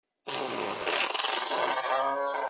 Basic Fart Téléchargement d'Effet Sonore
Basic Fart Bouton sonore